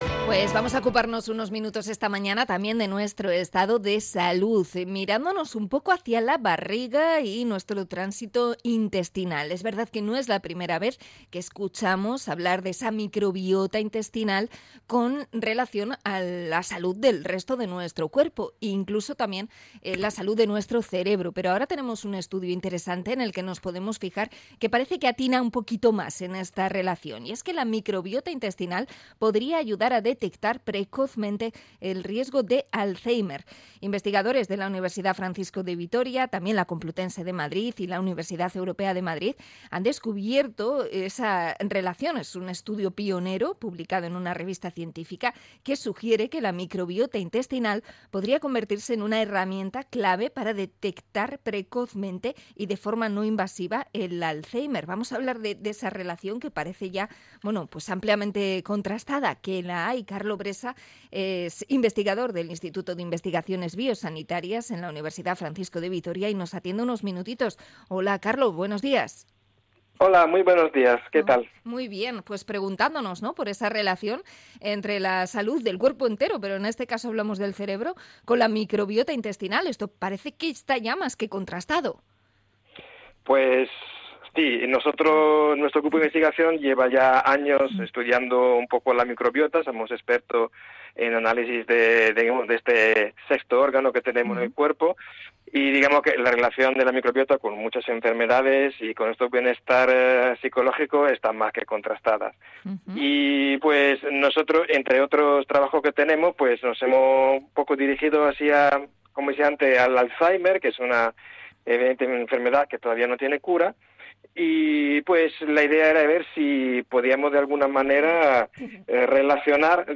Entrevista a investigador en microbiota sobre el riesgo de Alzheimer